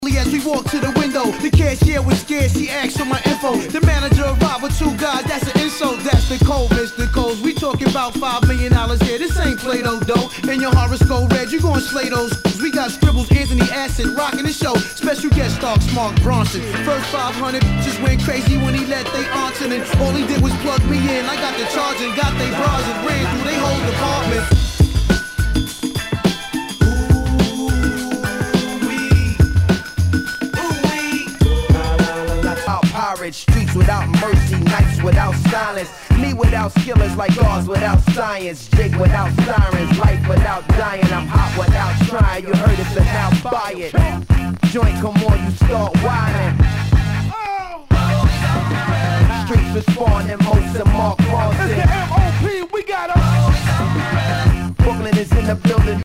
HOUSE/TECHNO/ELECTRO
ナイス！ヒップホップ！